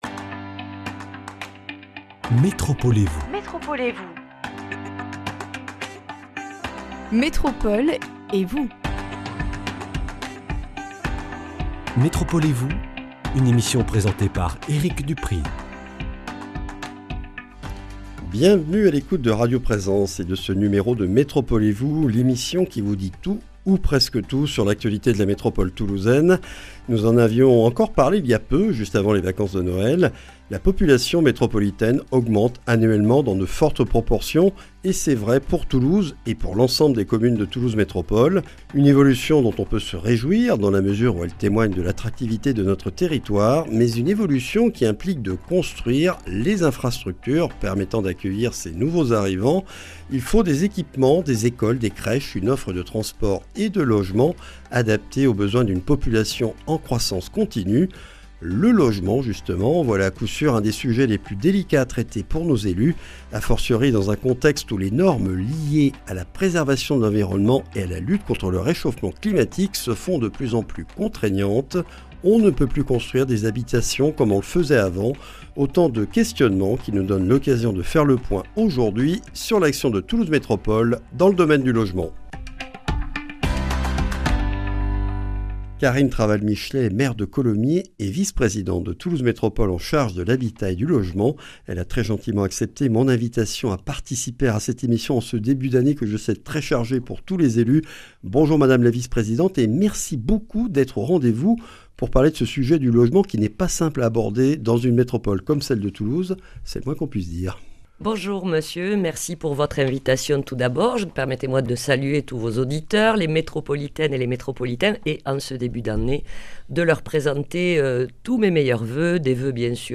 Karine Traval-Michelet, maire de Colomiers et vice-présidente de Toulouse Métropole chargée de l’Habitat et du Logement, est l’invitée de ce numéro consacré à la situation du logement dans la métropole toulousaine, sur un territoire à forte croissance démographique.